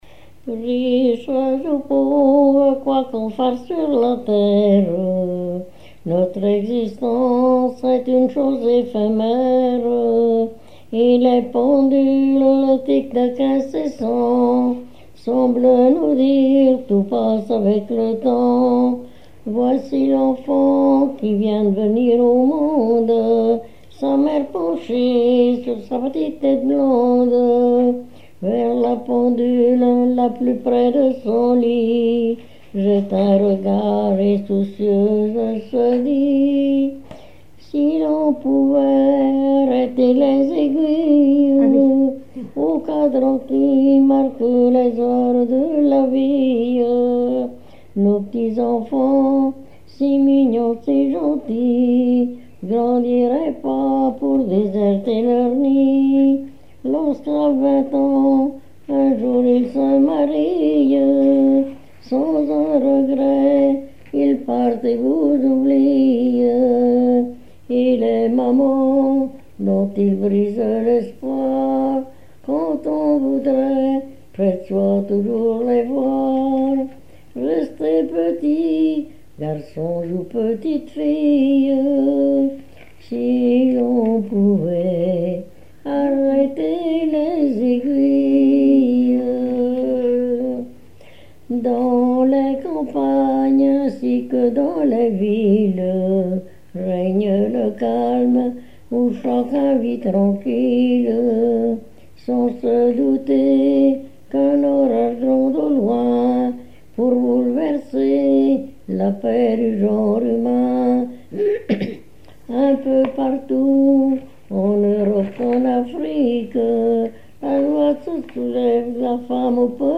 Mémoires et Patrimoines vivants - RaddO est une base de données d'archives iconographiques et sonores.
Genre strophique
Témoignages et chansons
Pièce musicale inédite